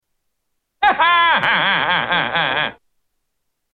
Laugh